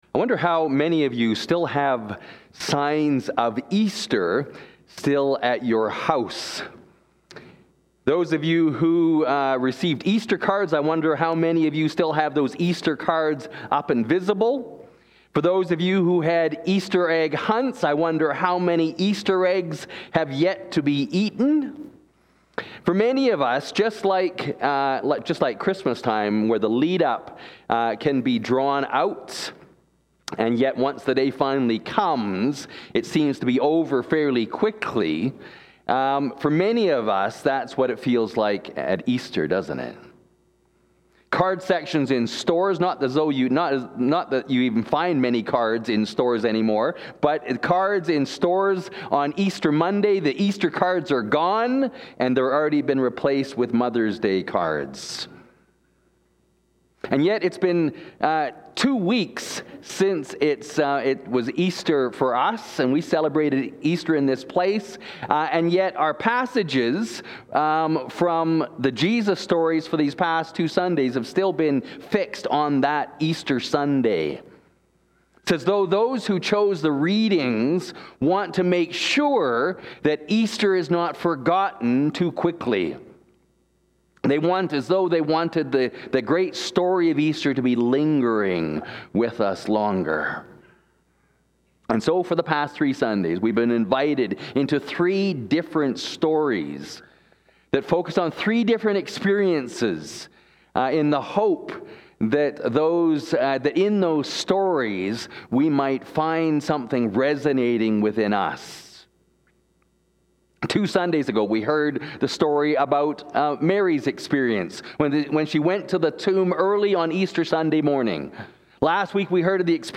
Luke 24:13-35 Please click here to view the sermon questions.